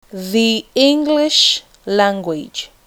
When the word begins with a vowel sound, “the” is pronounce /ði/ the 2.